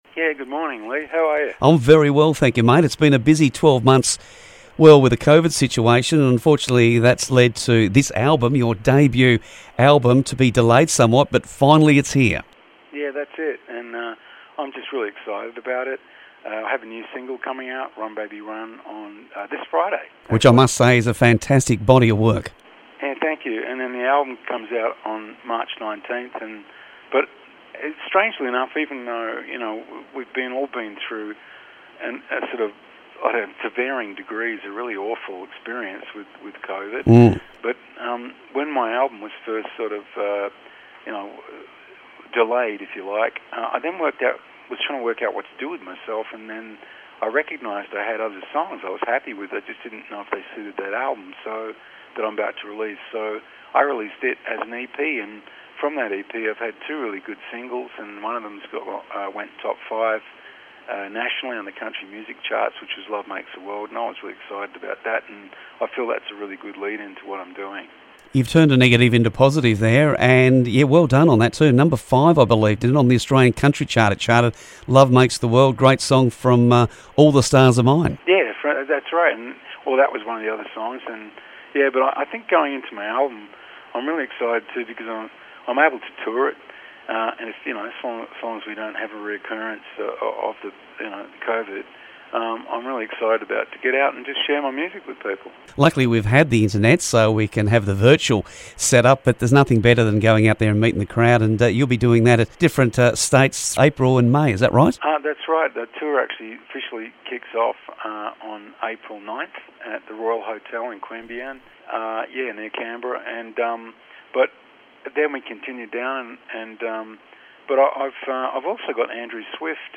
Andrew hardly needs an introduction to music fans, He is the man behind the songs of INXS and he releases his debut solo album and he spoke about it on 7BU Breakfast.